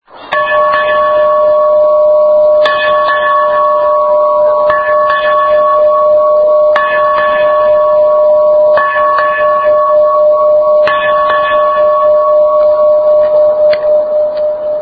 Double Ring (Ding – Dong)